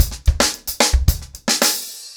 TimeToRun-110BPM.35.wav